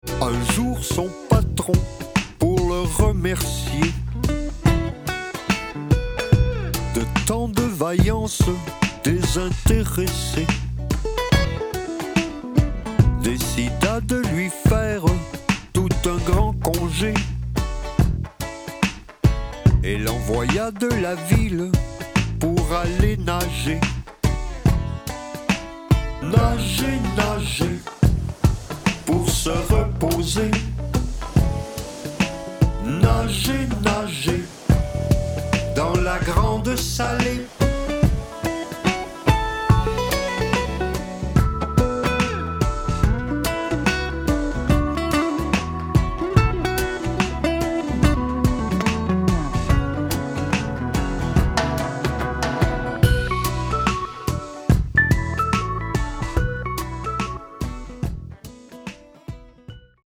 chante et  parle
guitariste
album électrique